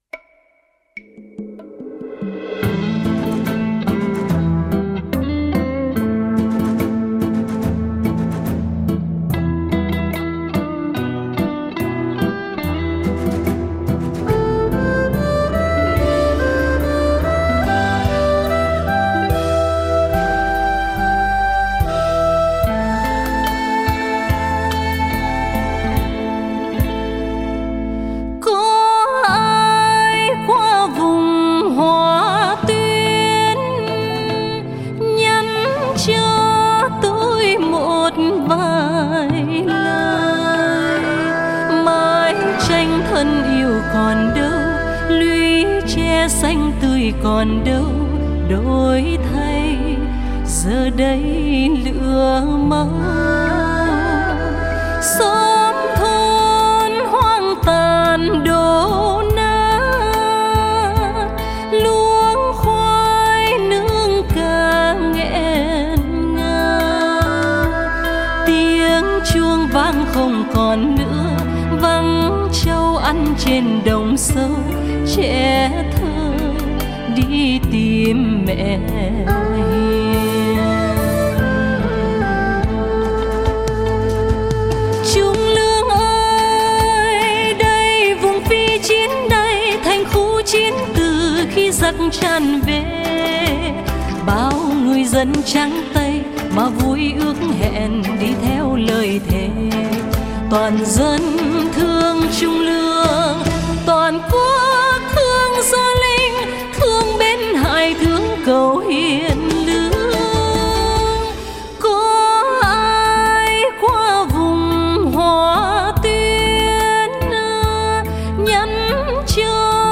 đơn ca